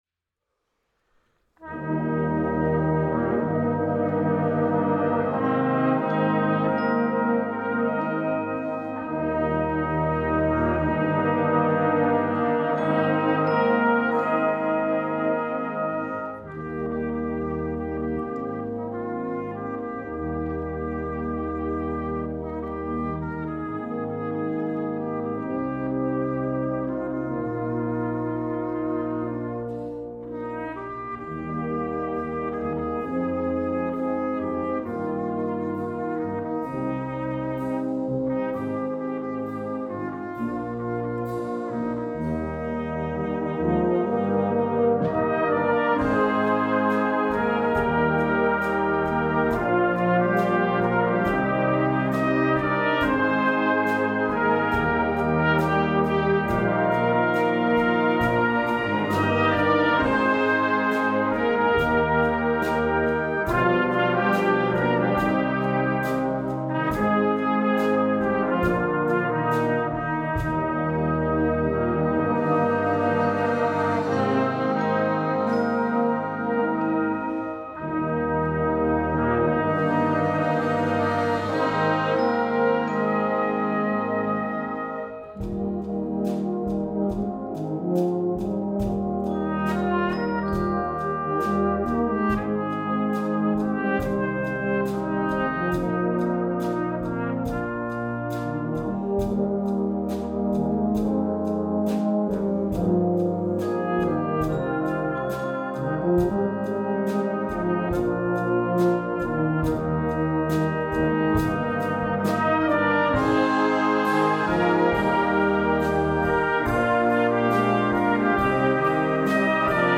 (Song Arrangement)